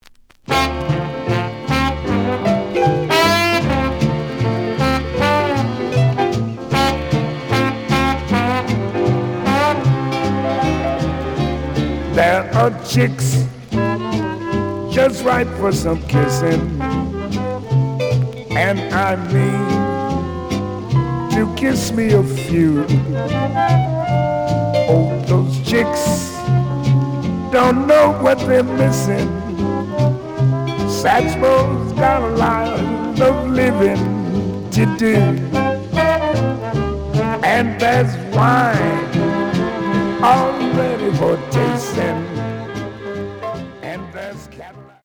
The audio sample is recorded from the actual item.
●Genre: Vocal Jazz
Slight sound cracking on both sides.